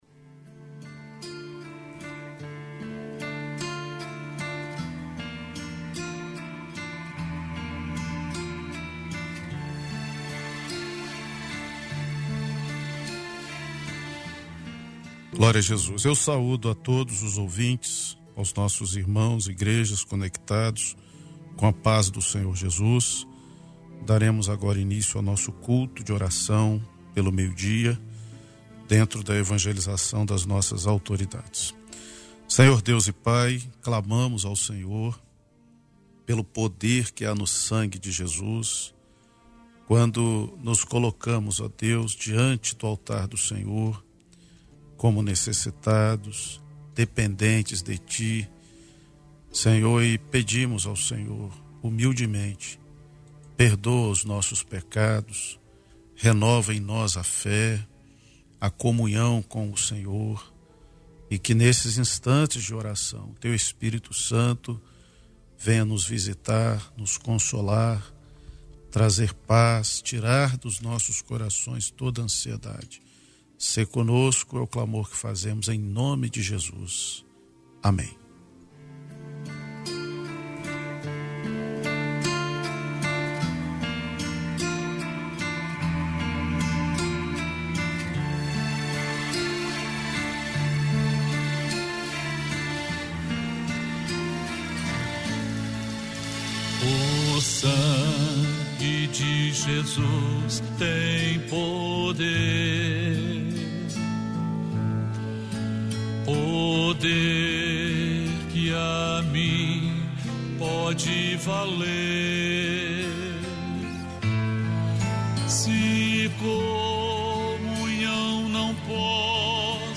Culto de oração da Igreja Cristã Maranata.